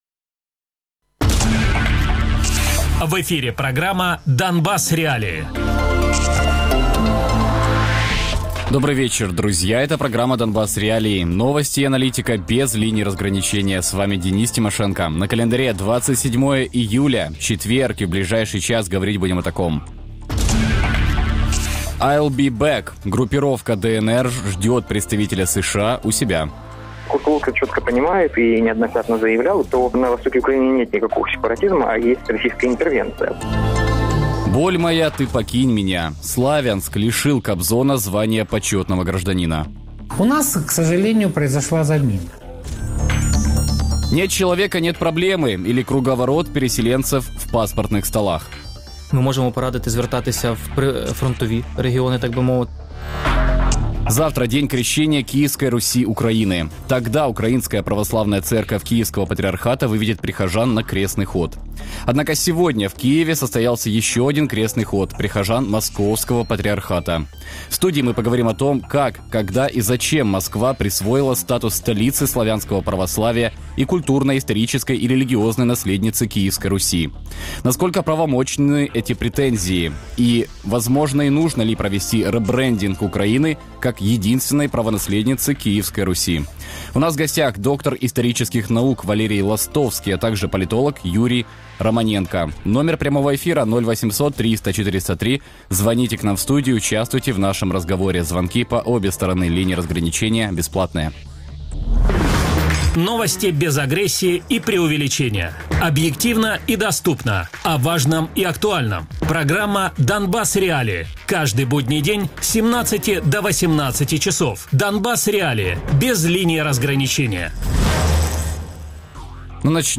Без агресії і перебільшення. 60 хвилин про найважливіше для Донецької і Луганської областей.